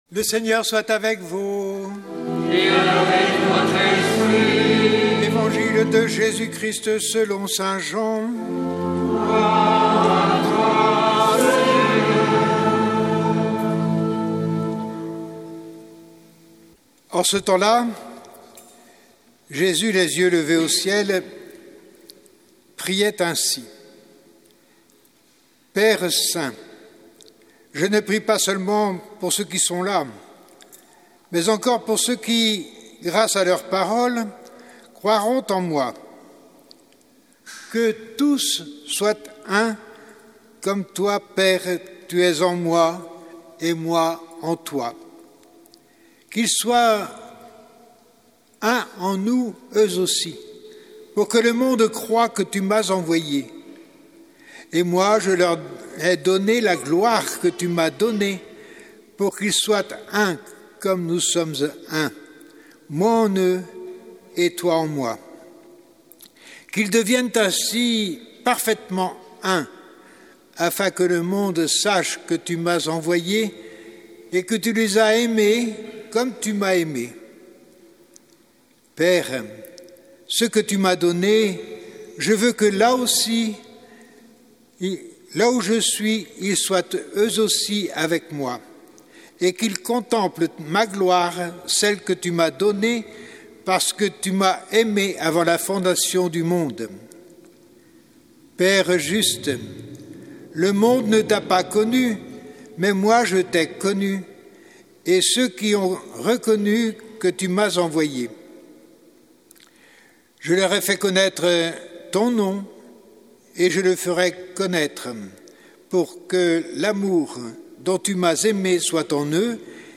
Évangile de Jésus Christ selon saint Jean avec l'homélie